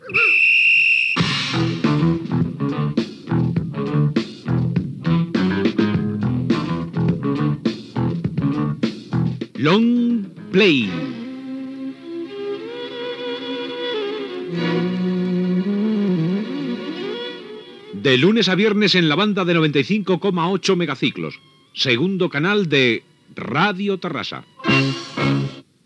Identificació del programa i de l'emissora